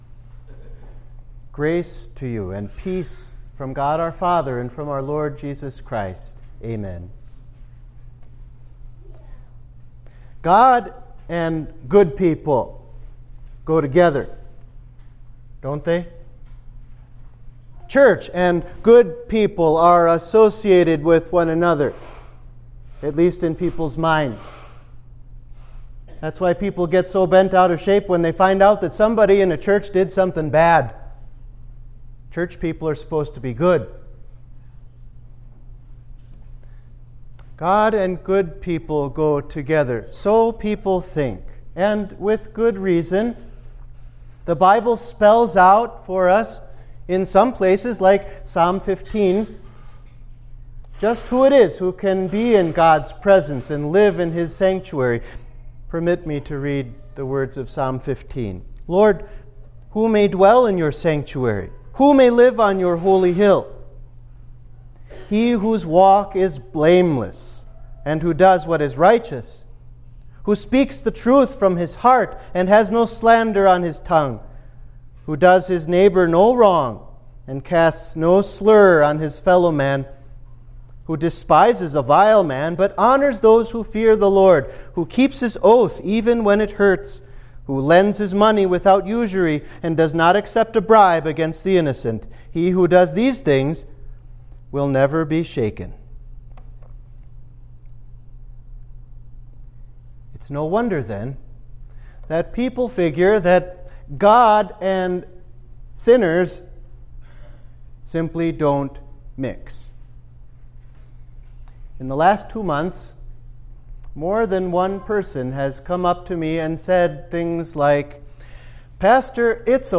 Sermon for the Third Sunday after Trinity